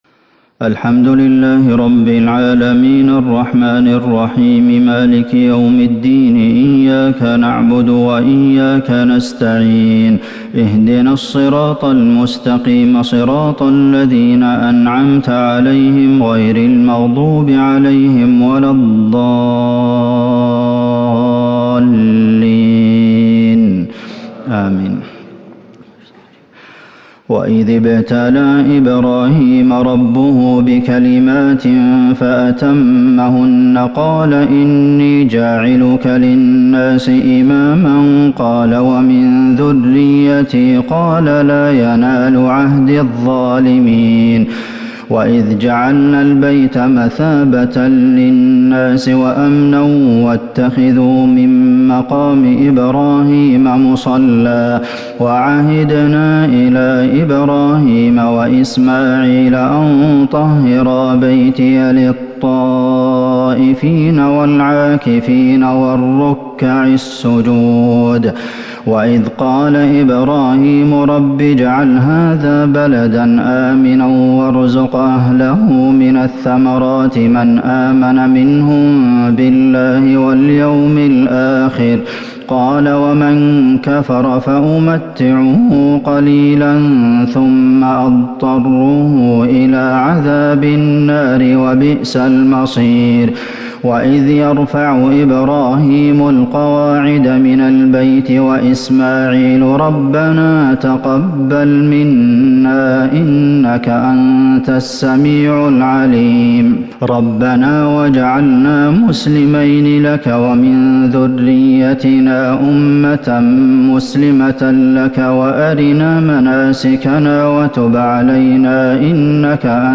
تهجد ٣٠ رمضان ١٤٤١هـ من سورة البقرة ١٢٤-١٦٧ > تراويح الحرم النبوي عام 1441 🕌 > التراويح - تلاوات الحرمين